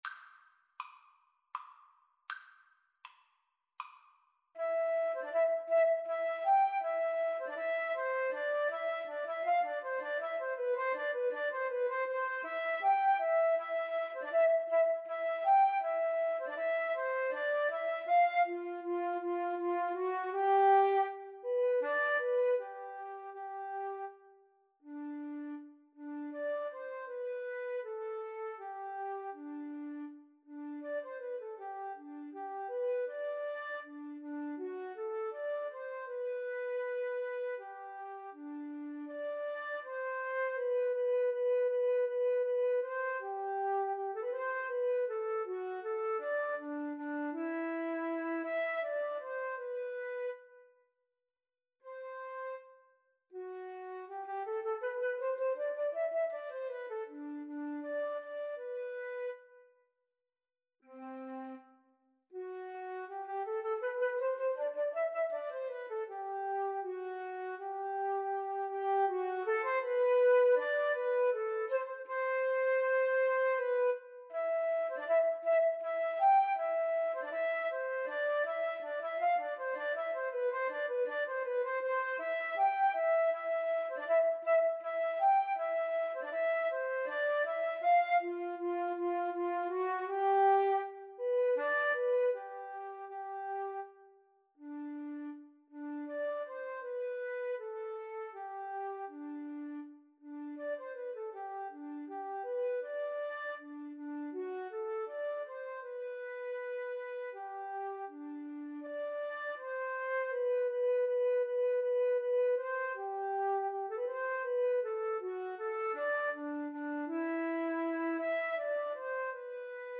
Free Sheet music for Flute Duet
Flute 1Flute 2
C major (Sounding Pitch) (View more C major Music for Flute Duet )
Andante
3/4 (View more 3/4 Music)
Classical (View more Classical Flute Duet Music)